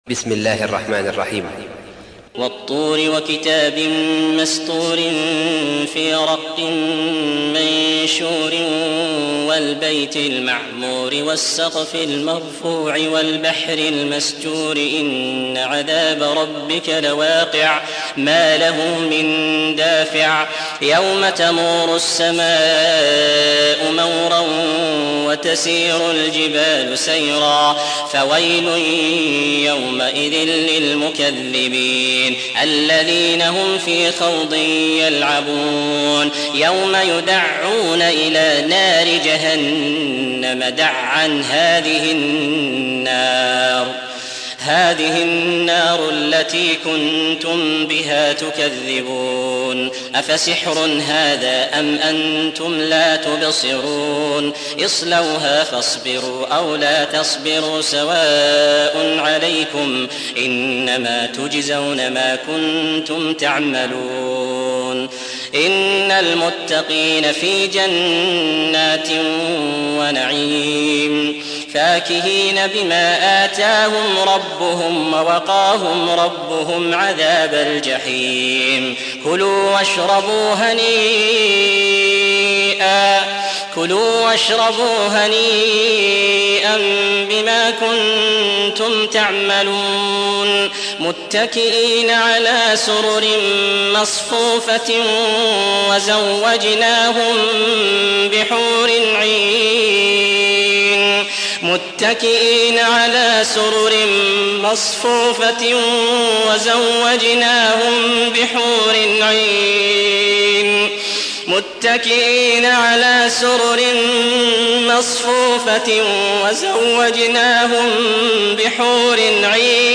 52. سورة الطور / القارئ